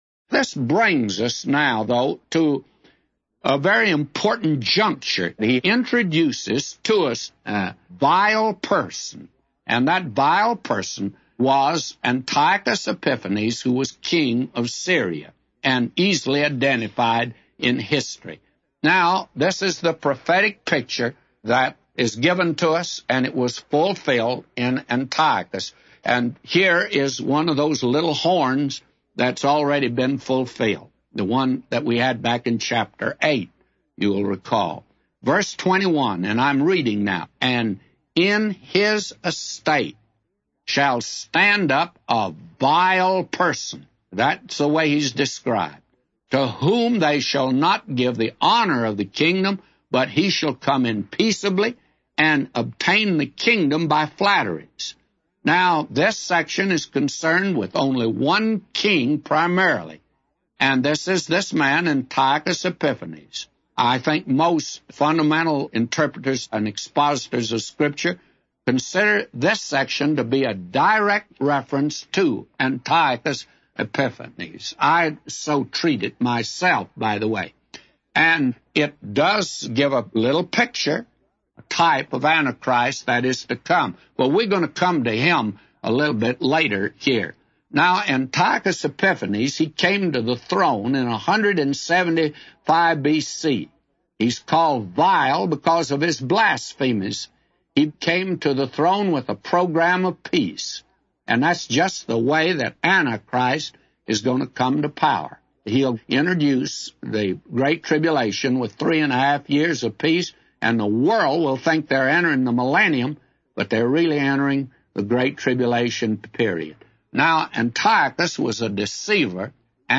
A Commentary By J Vernon MCgee For Daniel 11:21-999